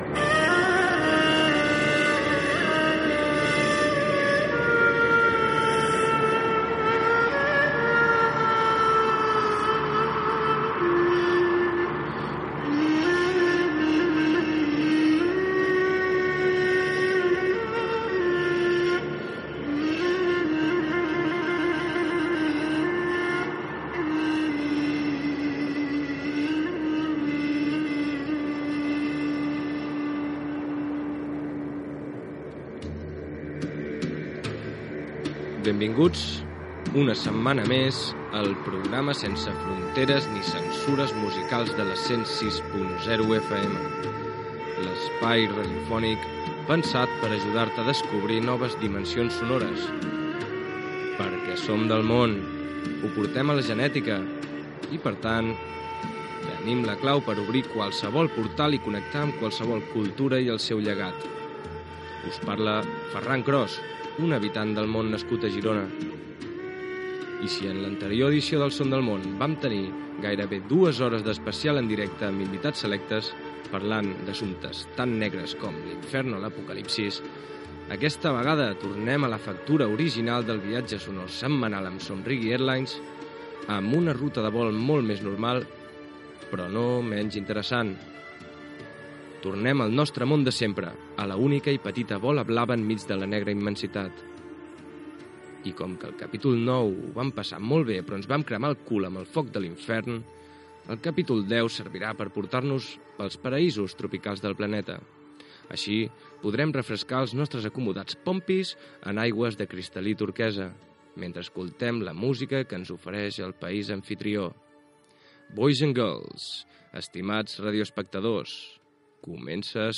Presentació de l'espai dedicat a "Un món tropical" Gènere radiofònic Musical